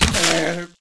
hell_dog4_get_hit.ogg